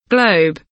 globe kelimesinin anlamı, resimli anlatımı ve sesli okunuşu